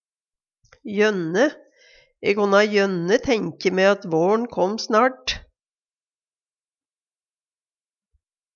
jønne (gjerne) - Numedalsmål (en-US)